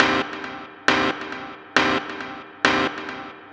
K-5 Stab Hi Filt.wav